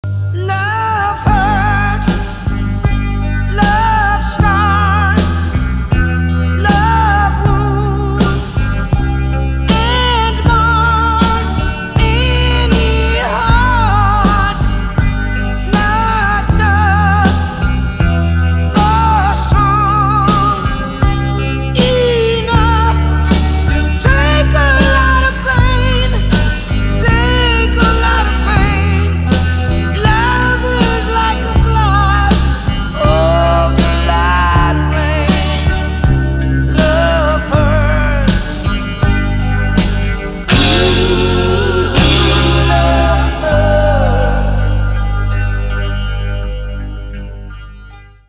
Романтические рингтоны